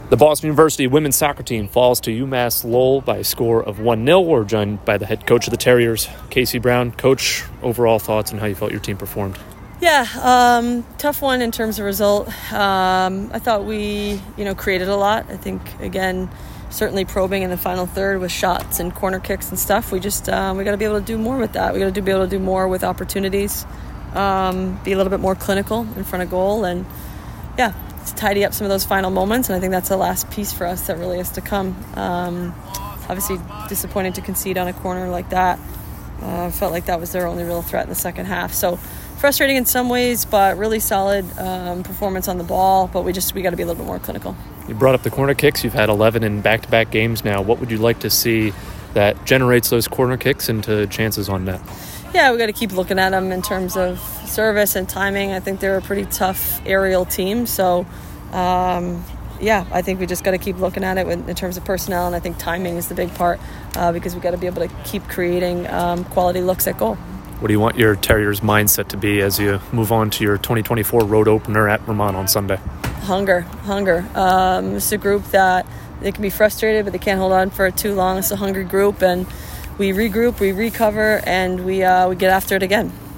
UMass Lowell Postgame Interview